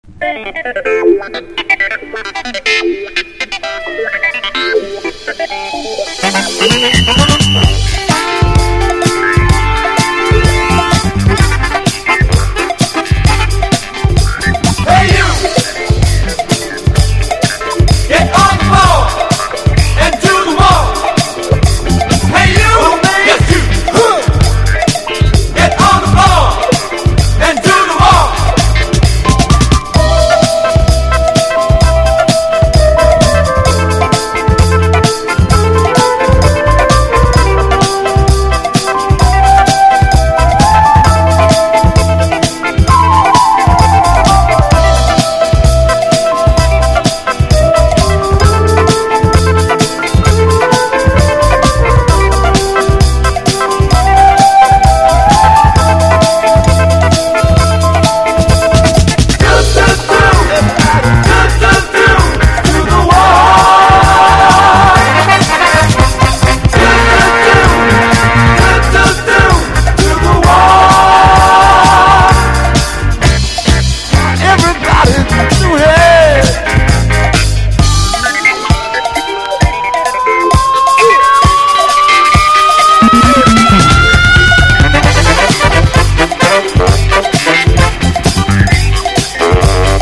これといった特徴はありませんが、色んなシーンに対応出来そうなDJには嬉しいソウルディスコ！！
所によりノイズありますが、リスニング用としては問題く、中古盤として標準的なコンディション。